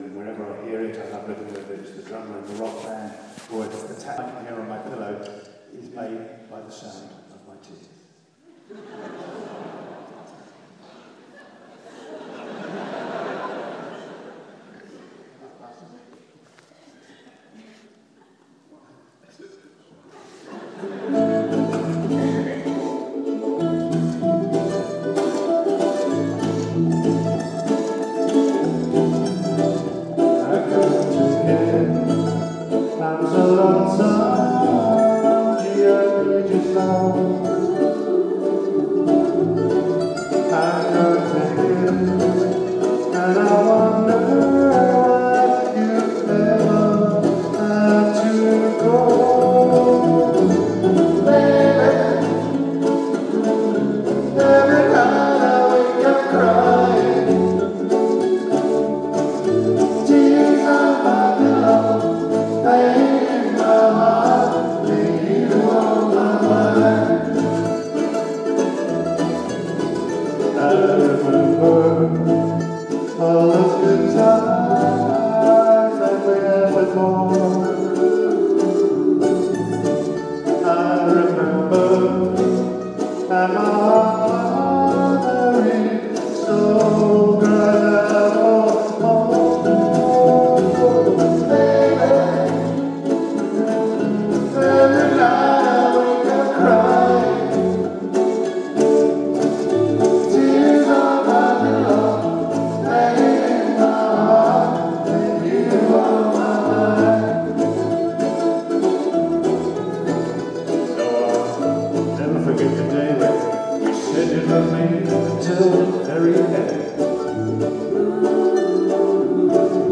What a fantastic night of entertainment.
ukelele